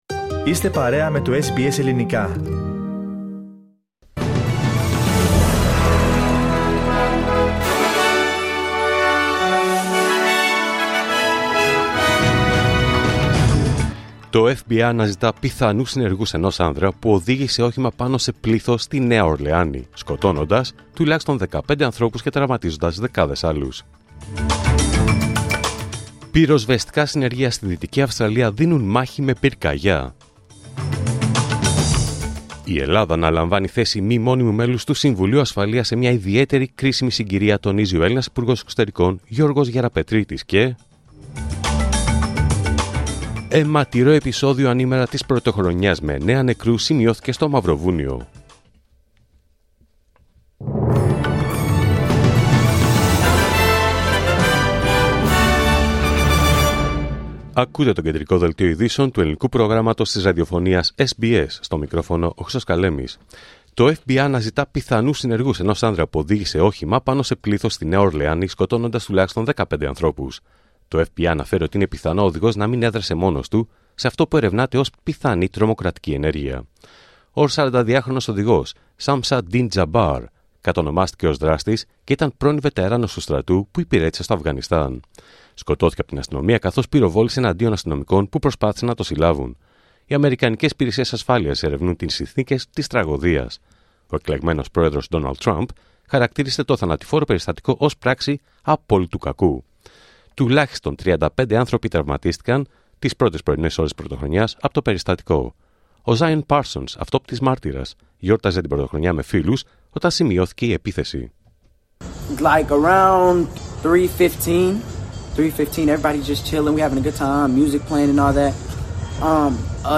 Δελτίο Ειδήσεων Πέμπτη 2 Ιανουαρίου 2025